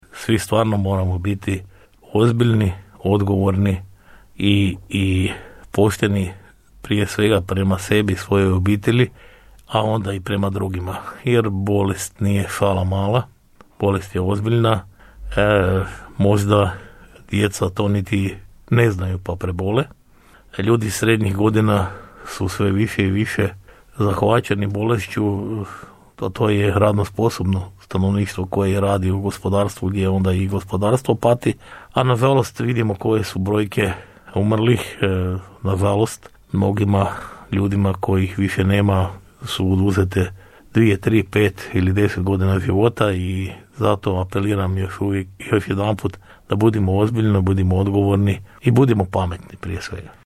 Gradonačelnik Ljubomir Kolarek u emisiji Osinjak
U srijedu je gostujući u emisiji Osinjak istaknuo: